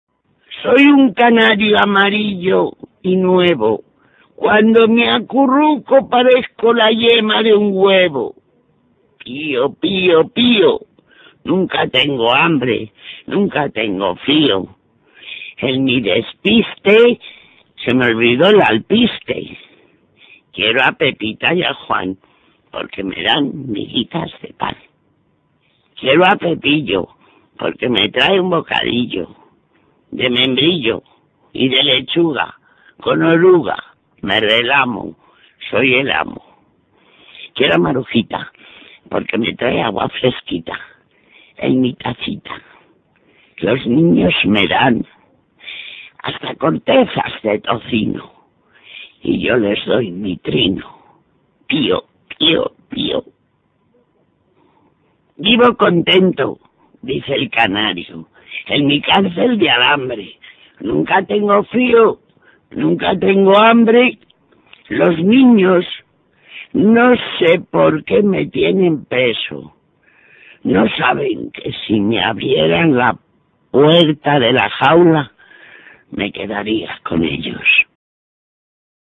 RECITAR UN POEMA